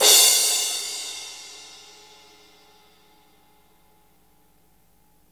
-CRASH 2  -R.wav